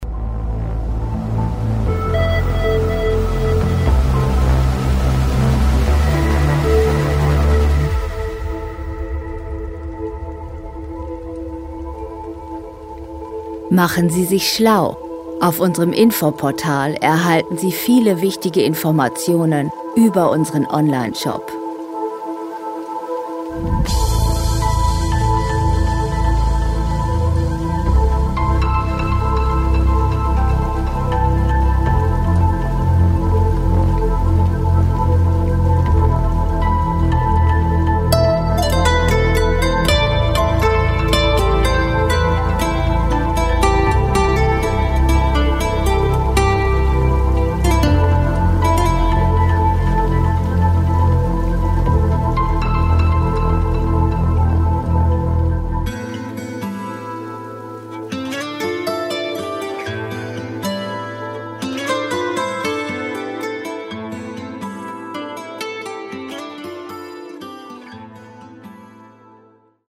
Filmmusik - Landschaften
Musikstil: Dark Ambient
Tempo: 60
Tonart: A-Moll
Charakter: pulsierend, düster
Instrumentierung: Synthesizer, Geräusche, Akustikgitarre